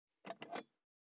207,食器,グラス,コップ,工具,小物,雑貨,コトン,トン,ゴト,ポン,ガシャン,ドスン,ストン,カチ,タン,バタン,スッ,サッ,コン,
コップ効果音物を置く